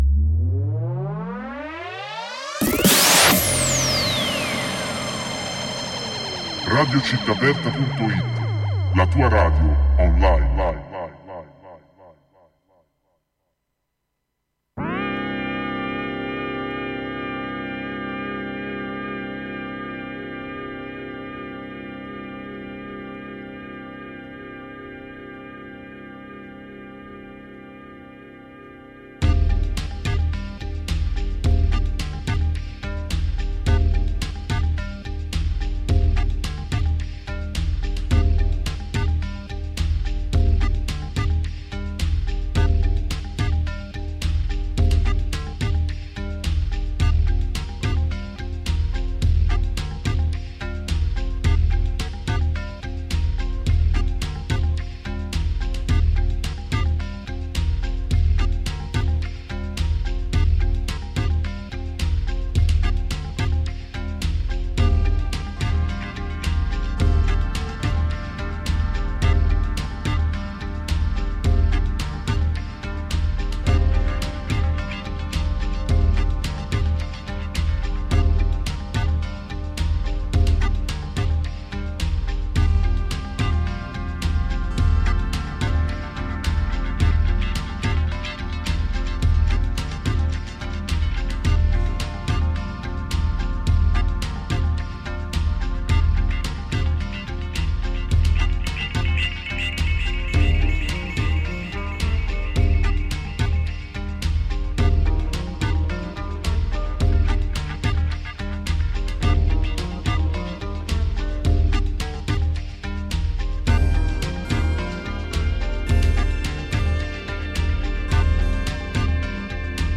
Si è ballato con punk funk e italo disco